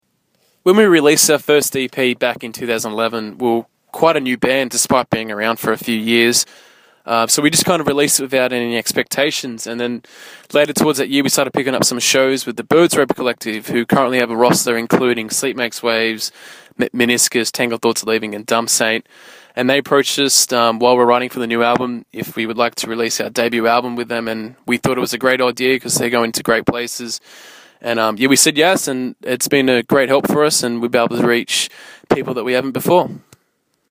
SOLKYRI INTERVIEW – May 2013